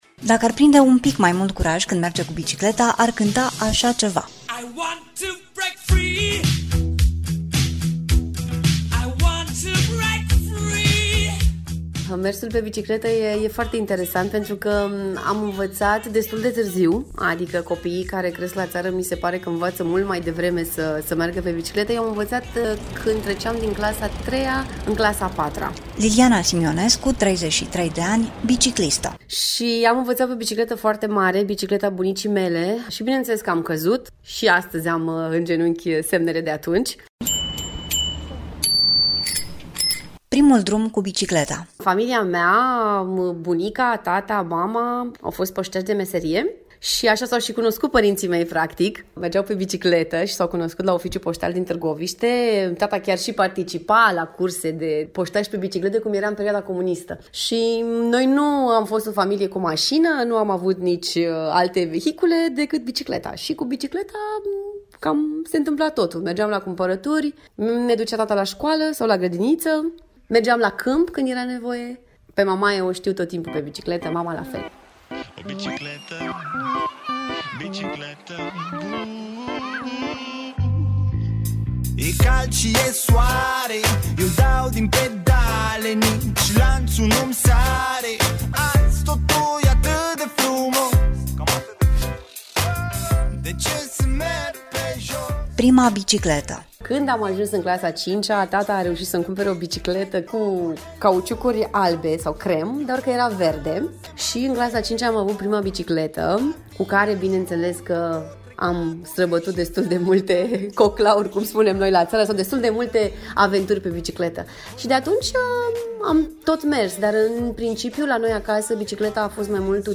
Povestea bicicletei roșii – Reportaj